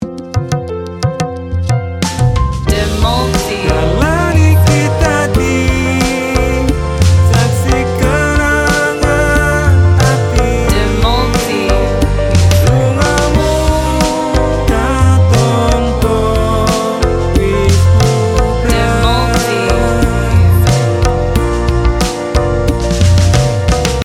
Ada kesedihan, tapi dibungkus dengan energi hidup.